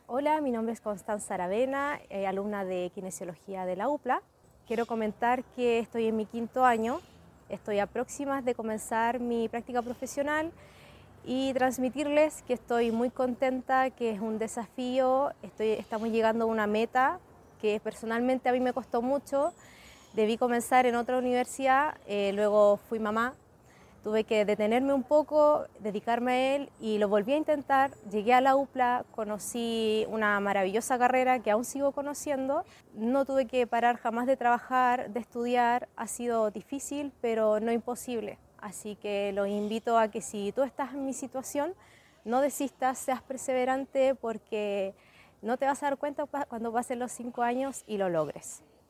Compartimos con ustedes parte de lo que conversamos con algunos estudiantes, quienes desde su historia personal, evaluaron lo que significa para ellos, el inicio de sus prácticas profesionales, a fines de este mes.
Testimonios